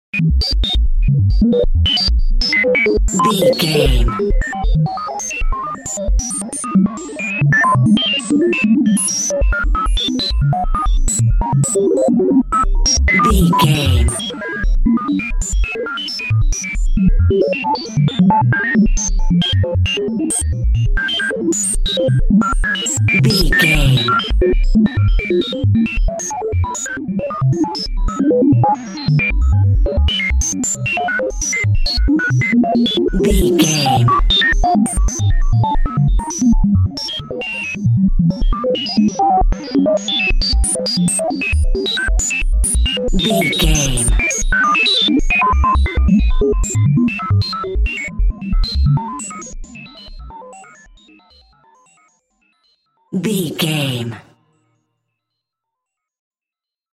Robotic Music.
In-crescendo
Aeolian/Minor
tension
ominous
eerie
Horror synth
Horror Ambience
electronics
synthesizer